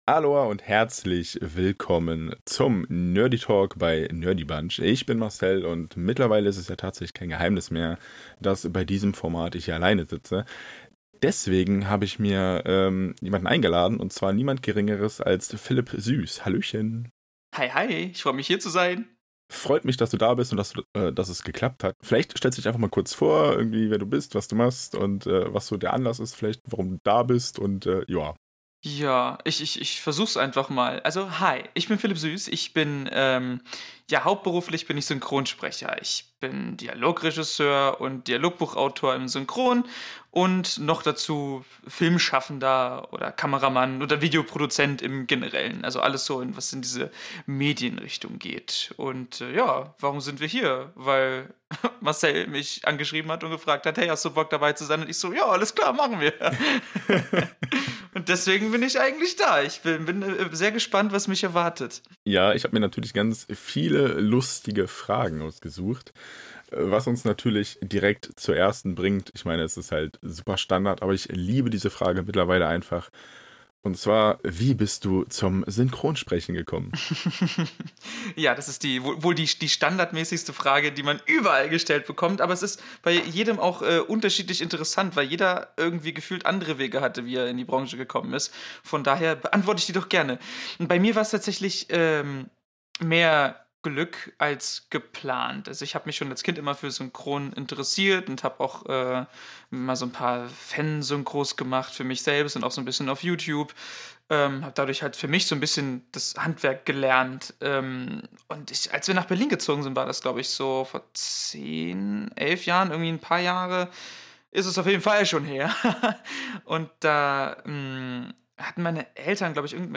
stellt sich in diesem Nerdytalk den Fragen